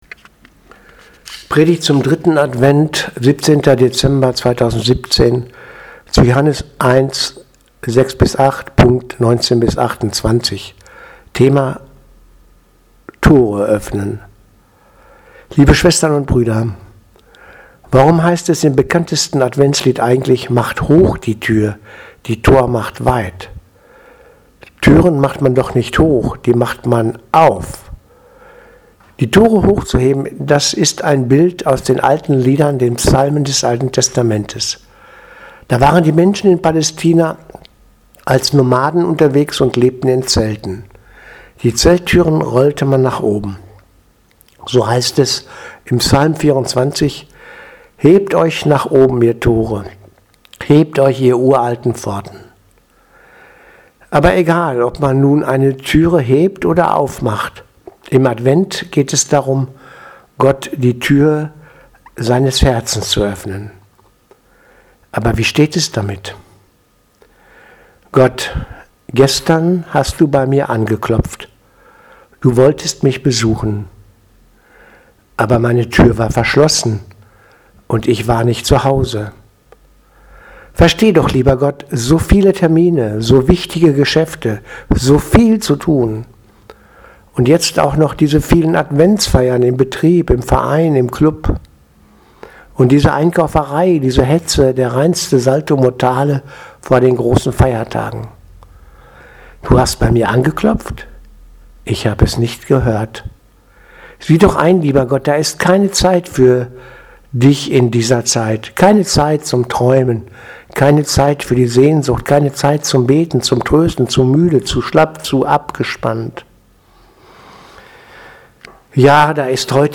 Predigt vom 17.12.2017 3.Advent